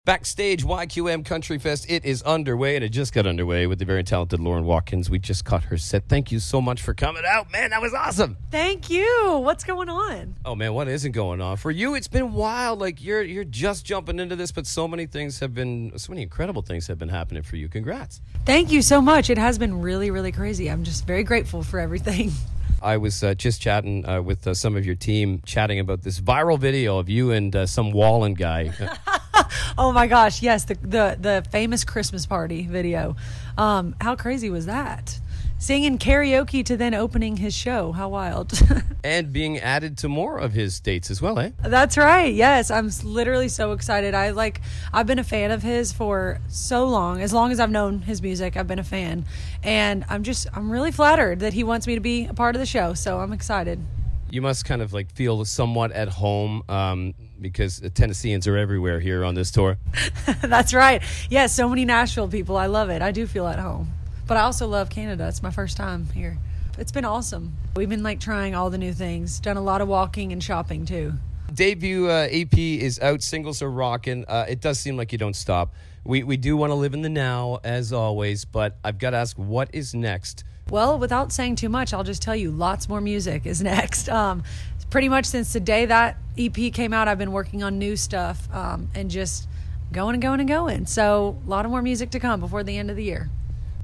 I had the chance to chat with her and we HAD to talk about her viral video with Morgan Wallen.